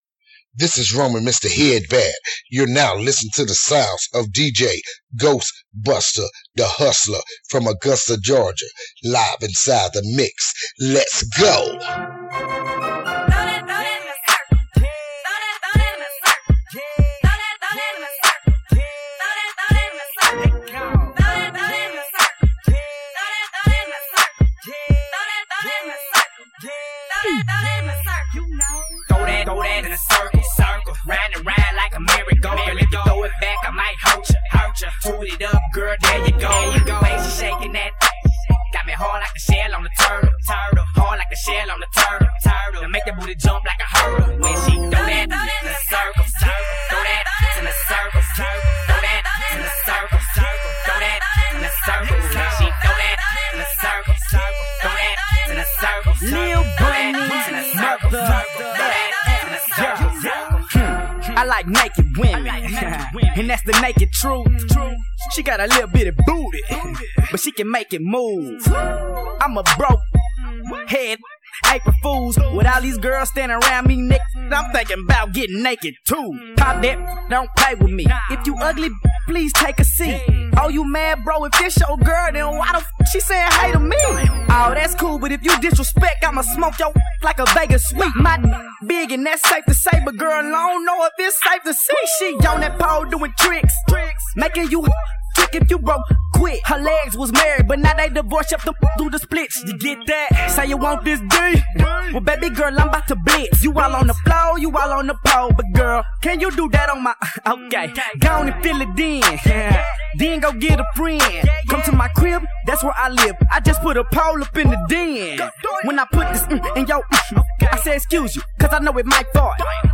Hiphop
Blazing New School Hip-Hop